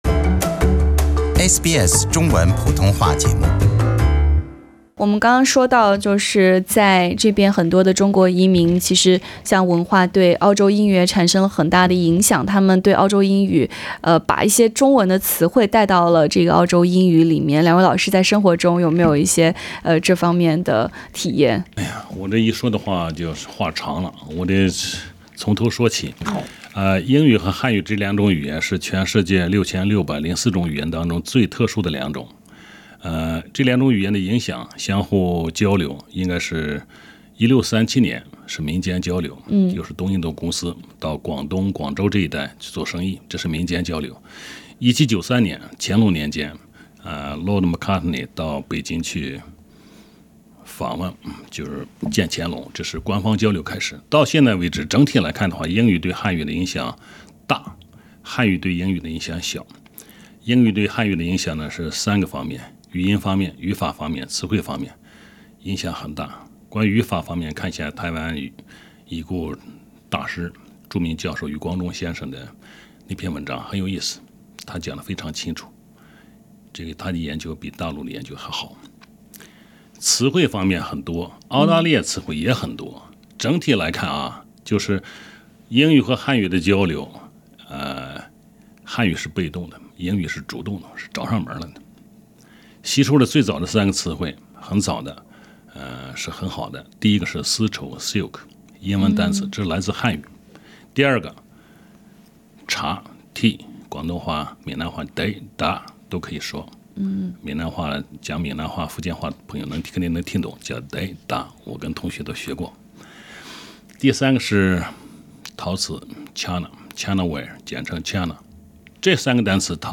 欢迎收听SBS 文化时评栏目《文化苦丁茶》，今天的话题是：翠花or Jessica请注意：澳式英语将风行世界（第2集）。